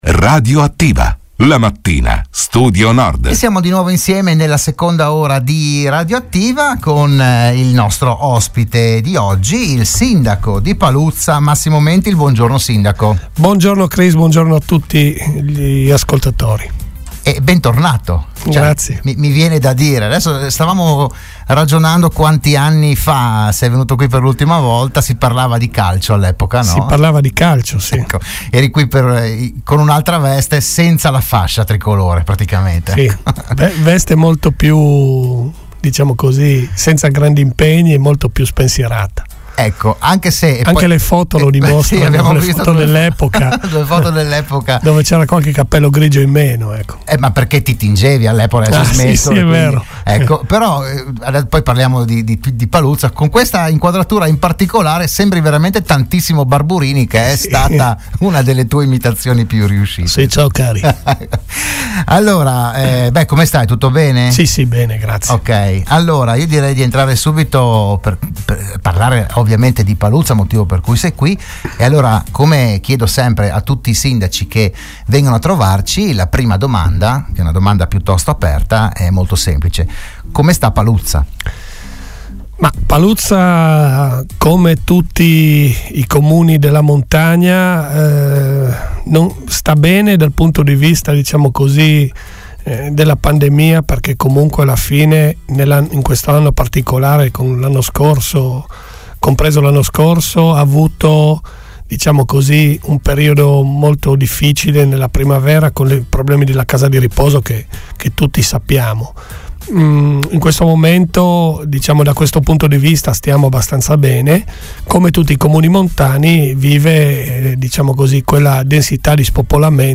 Ogni settimana il primo cittadino di un comune dell’Alto Friuli è ospite in studio, in diretta (anche video sulla pagina Facebook di RSN), per parlare del suo territorio, delle problematiche, delle iniziative, delle idee, eccetera.
Al sesto appuntamento del 2021 ha partecipato il sindaco di Paluzza Massimo Mentil.